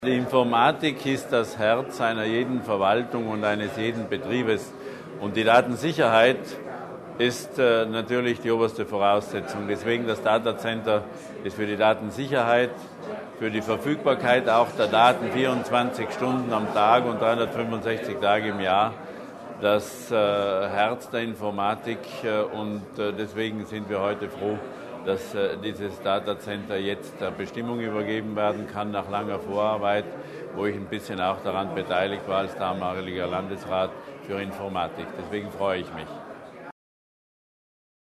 Landesrat Berger zur Fertigstellung des Projektes